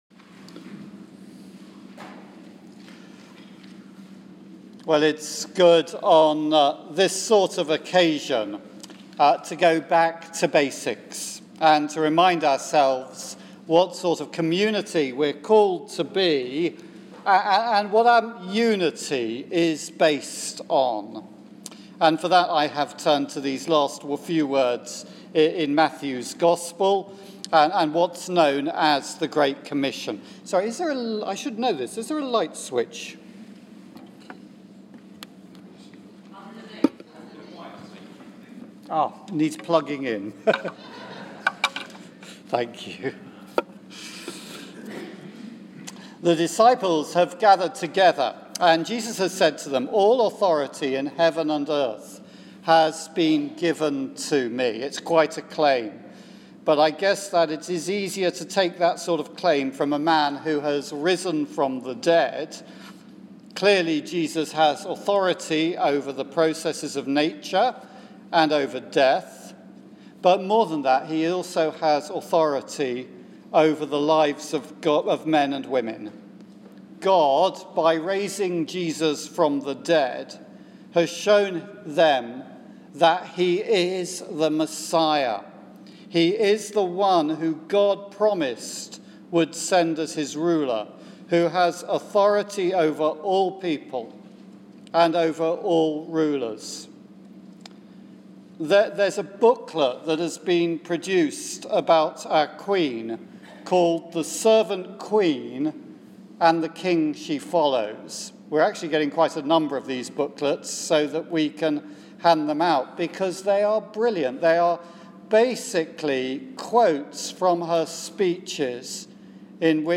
Sermon on Matthew 28:16-20.